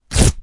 描述：用手被剥去和打破的干树皮。在Zoom H4上录制。轻压缩。 WAV 44.1
标签： 翻录 翻录 断裂 质地 现场记录 树皮
声道立体声